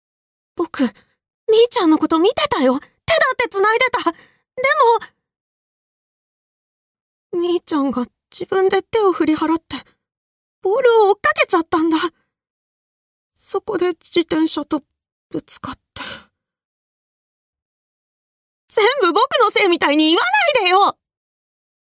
↓ここからはボイスサンプルです。
少年・中高生